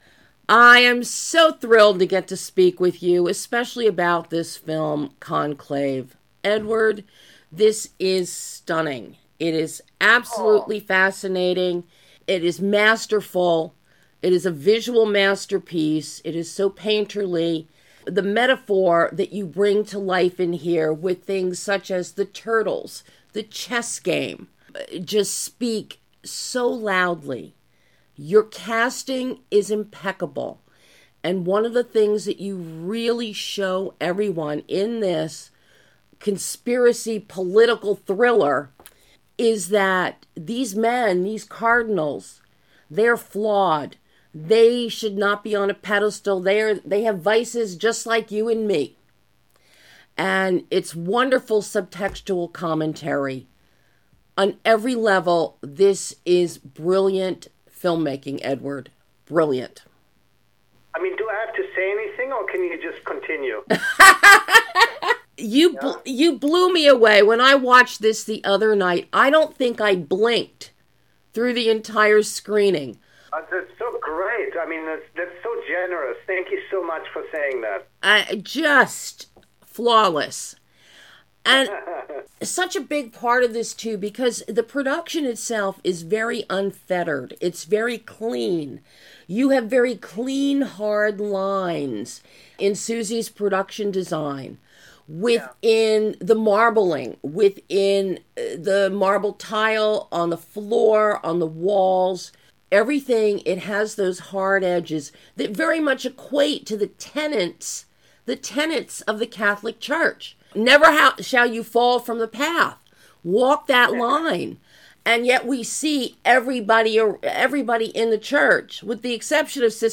Director EDWARD BERGER discusses the masterful and powerful CONCLAVE - Exclusive Interview
An insightful in-depth exclusive interview with director EDWARD BERGER discussing the masterful and fascinating CONCLAVE.